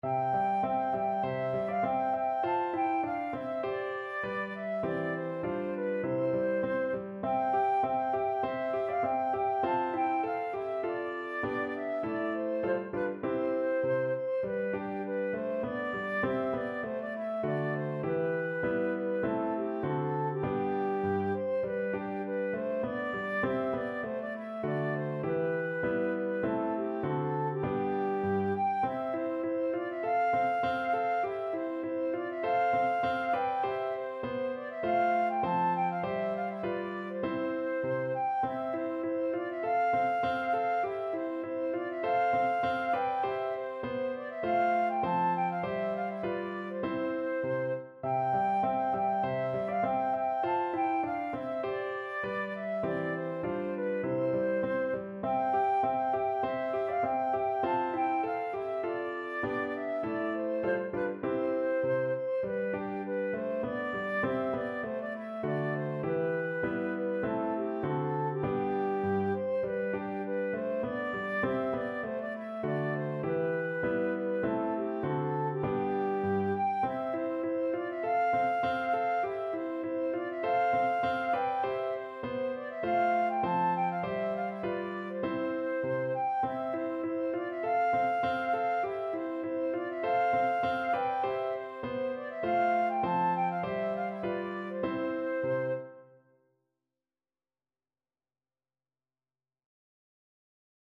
Traditional Trad. Gathering Peascods from John Playford's 'Dancing Master' Flute version
Flute
G5-A6
C major (Sounding Pitch) (View more C major Music for Flute )
~ = 200 A1
2/2 (View more 2/2 Music)
Traditional (View more Traditional Flute Music)